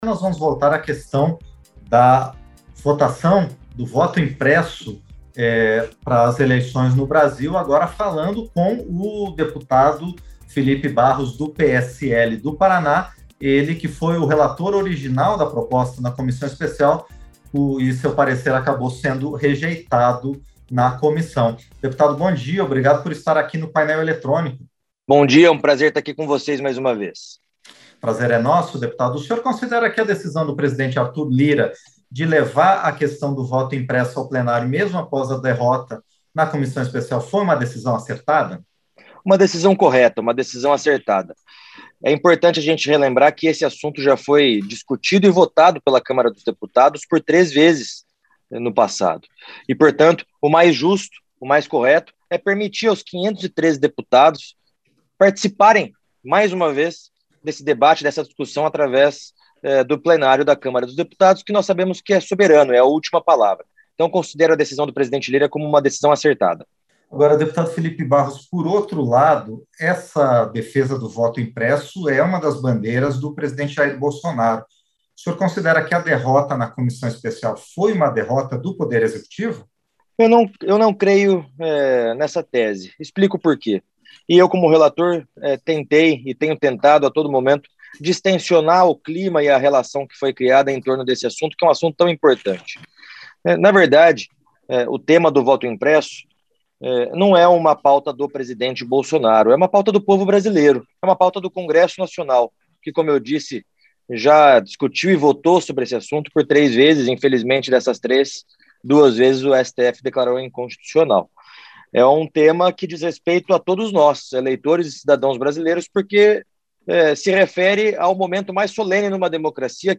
• Entrevista - Dep. Filipe Barros (PSL-PR)
Programa ao vivo com reportagens, entrevistas sobre temas relacionados à Câmara dos Deputados, e o que vai ser destaque durante a semana.